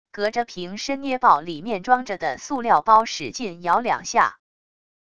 隔着瓶身捏爆里面装着的塑料包使劲摇两下wav音频